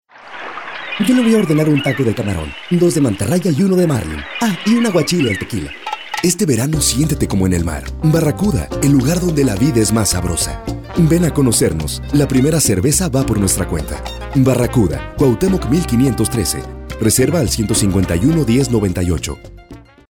natural, friendly, believable Male Voice Over Talent
Vocal Range: 15 to 60 years Voice Type: medium - severe - deep Intentions: friendly, corporate, elegant, selling, corporate, serious, intelligent, captivating, neutral, sports, loving, warm, cold, blue, bright, monstrous, etc, etc ...
VOICE ACTOR DEMOS